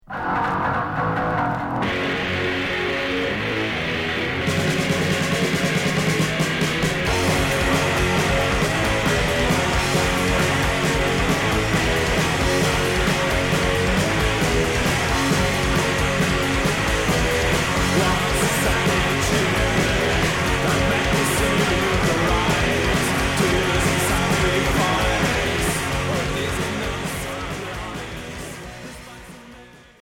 Rock garage punk Quatrième 45t retour à l'accueil